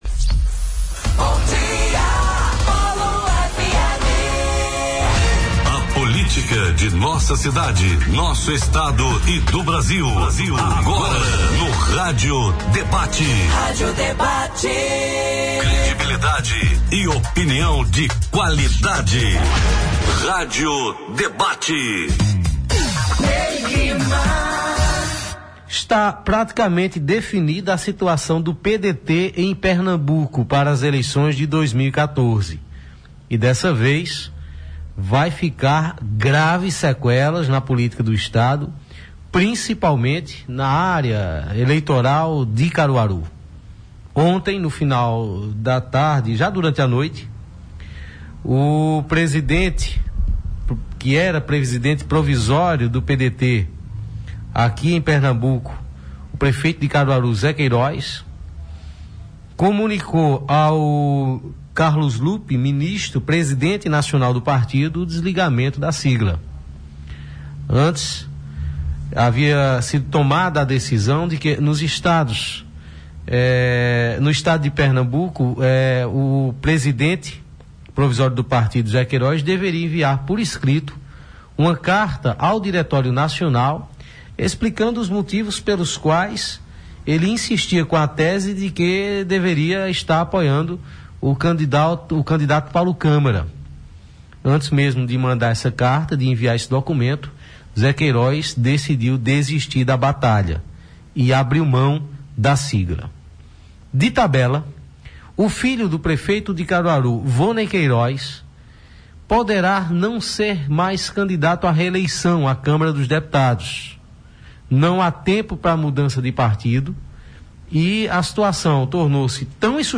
“A Hora do Debate” aborda as afirmações do ex-deputado Oseas Moraes A segunda parte do programa Rádio Debate foi transmitido ao vivo direto do Rota do Mar Complex, a abordou a entrevista do ex-prefeito e pré-candidato a deputado estadual Toinho do Pará (PHS) concedida a Rádio Líder FM do município de Toritama.